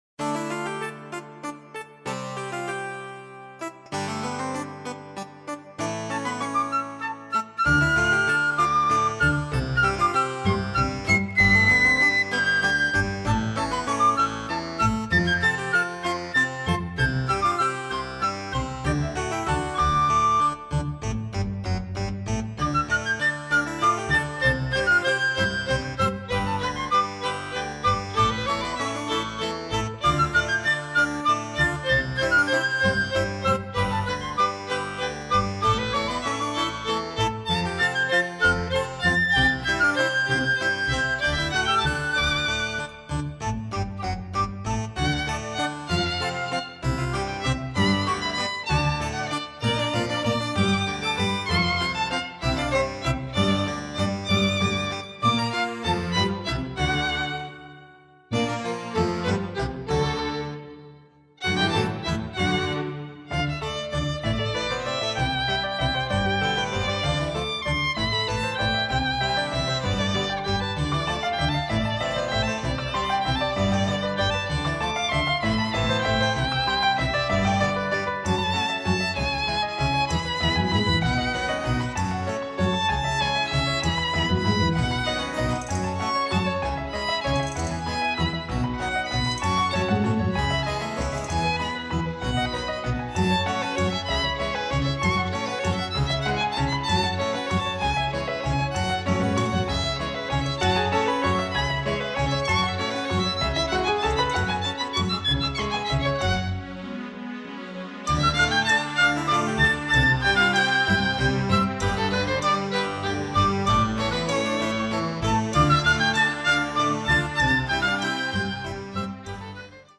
プログレです！